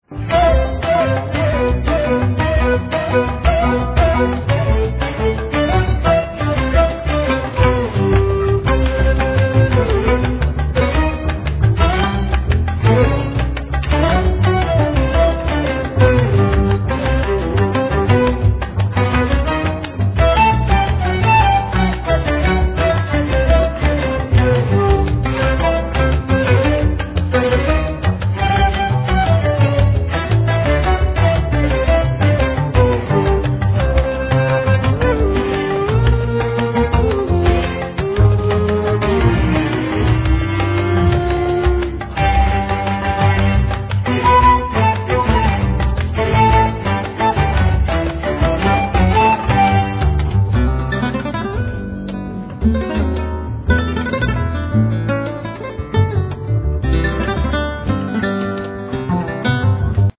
Guitar
Bandolim